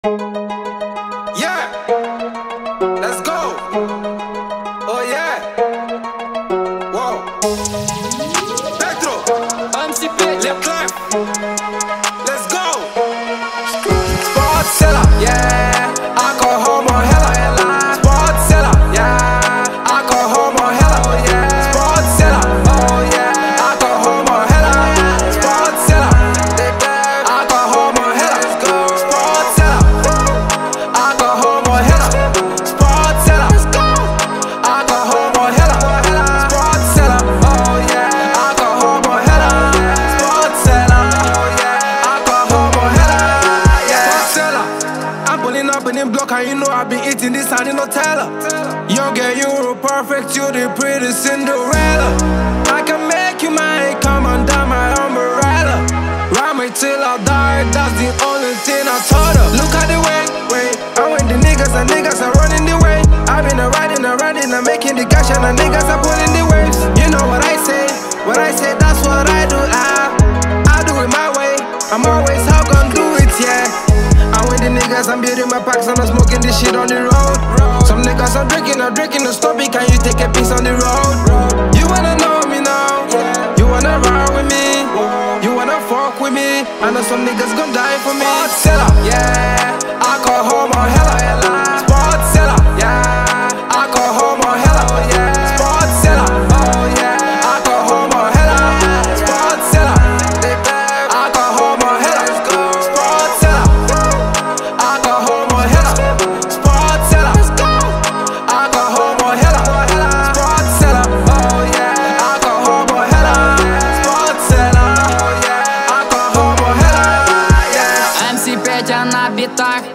Категория: R`n`B